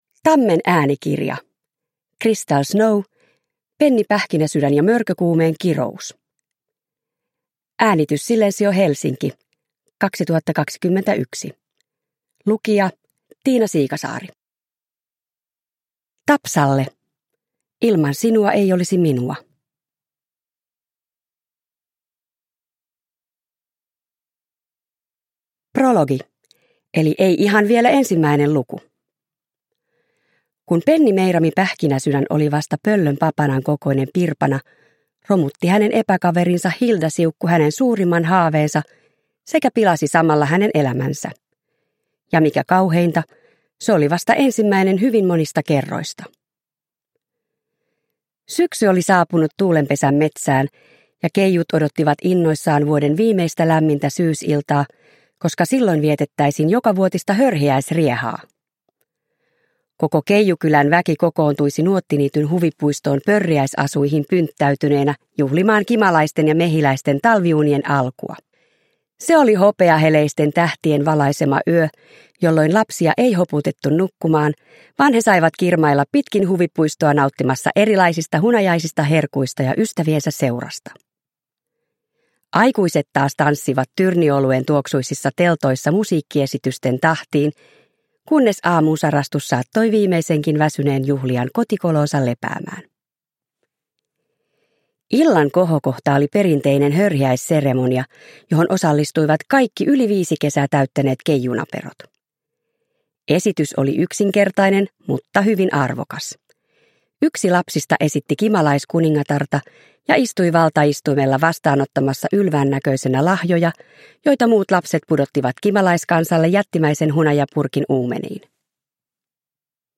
Penni Pähkinäsydän ja mörkökuumeen kirous – Ljudbok – Laddas ner